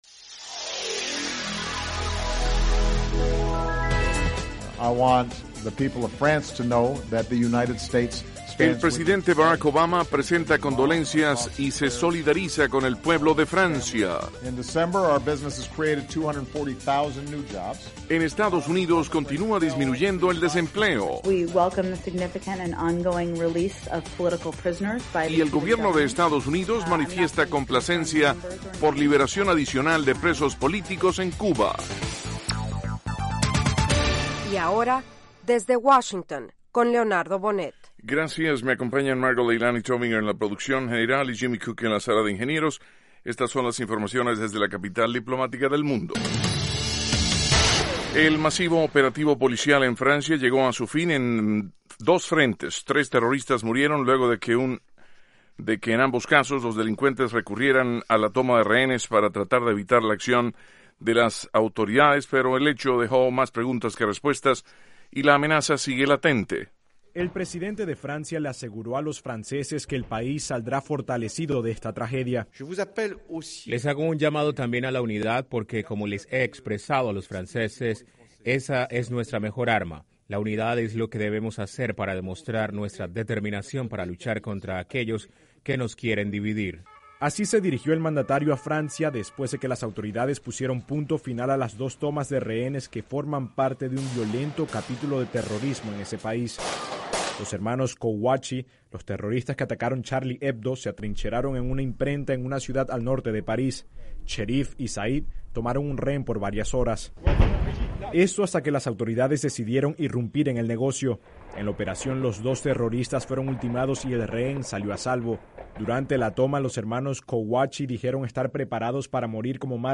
Diez minutos de noticias sobre los acontecimientos de Estados Unidos y el mundo.